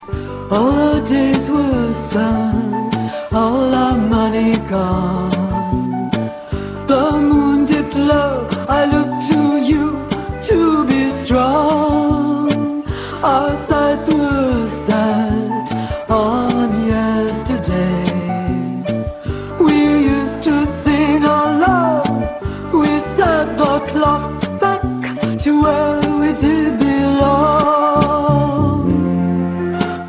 Real Audio/mono